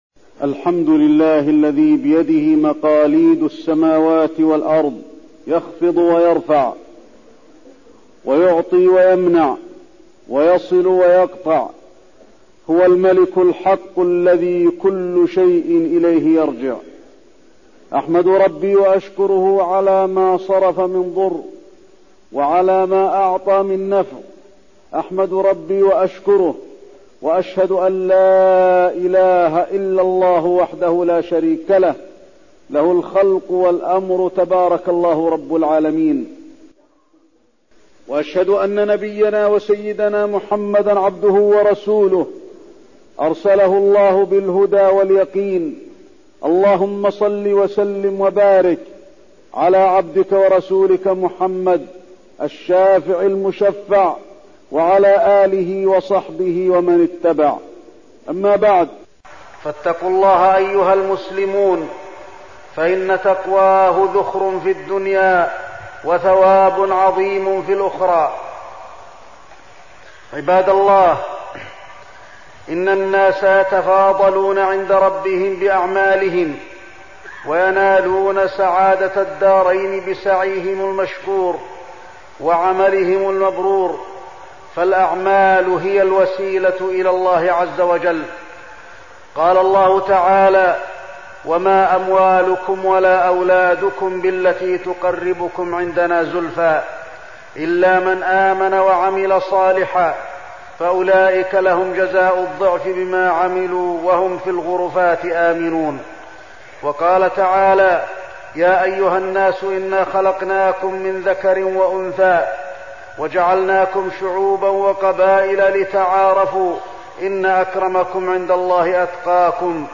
تاريخ النشر ٢ ربيع الثاني ١٤١٧ هـ المكان: المسجد النبوي الشيخ: فضيلة الشيخ د. علي بن عبدالرحمن الحذيفي فضيلة الشيخ د. علي بن عبدالرحمن الحذيفي التقوى